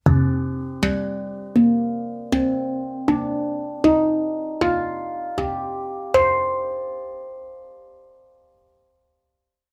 Moon II Handpan i C Low Pygmy (Ø 55 cm) er laget av rustfritt stål og gir en jordnær, mystisk og meditativ klang.
• Stemning: C Low Pygmy – dyp, mystisk og meditativ klang.
• Lang sustain med rike overtoner.
C3, G3, Bb3, C4, D4, D#4, F4, G4, C5